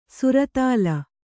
♪ suratāla